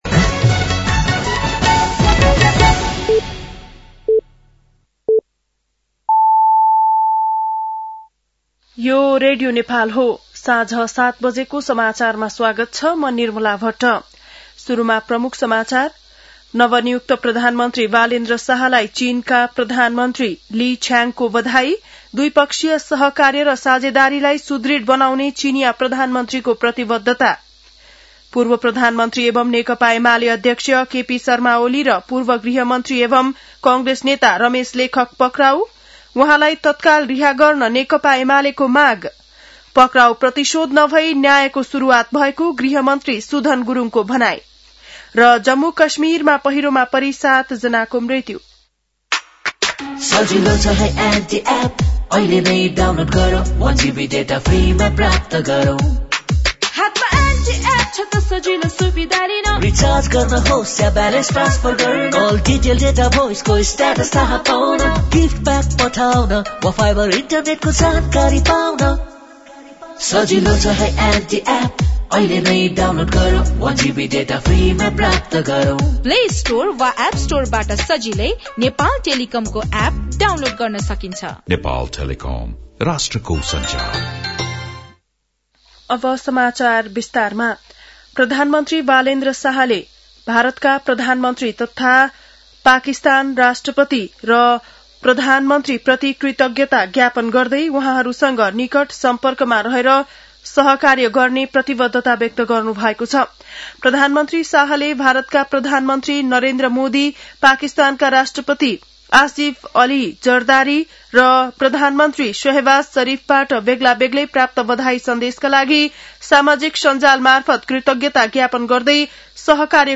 बेलुकी ७ बजेको नेपाली समाचार : १४ चैत , २०८२
7.-pm-nepali-news-1-2.mp3